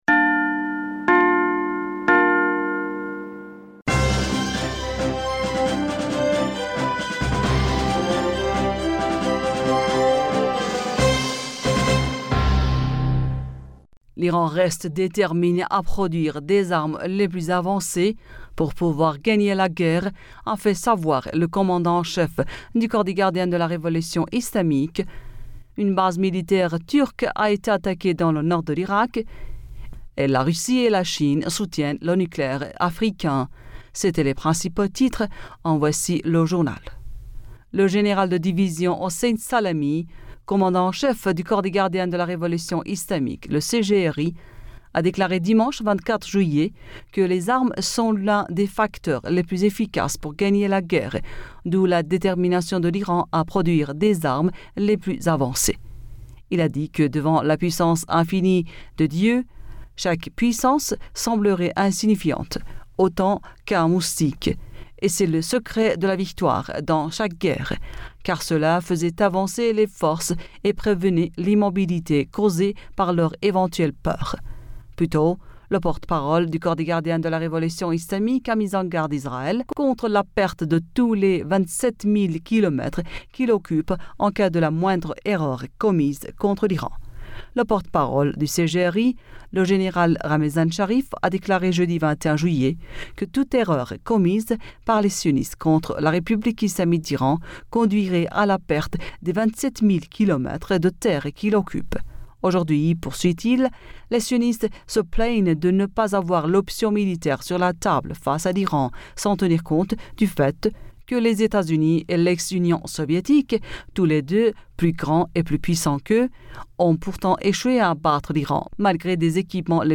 Bulletin d'information Du 25 Julliet